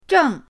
zheng4.mp3